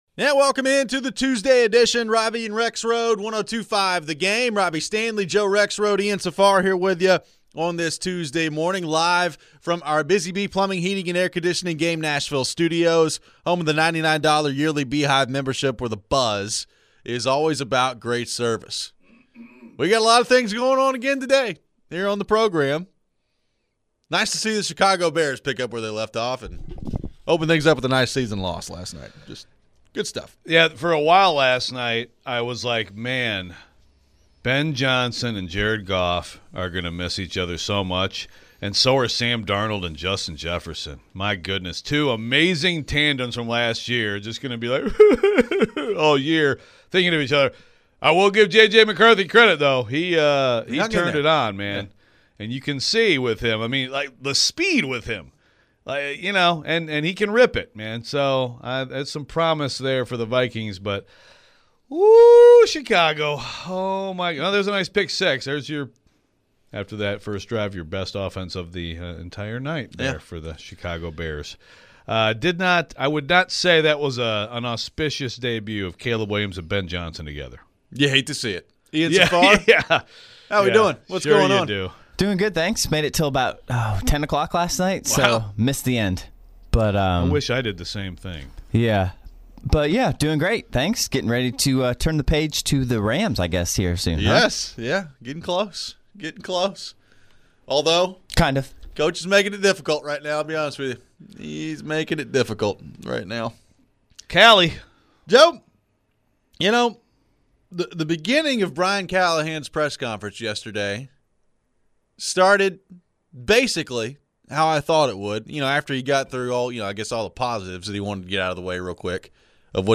How much does this damage him in the locker room? We take your phones.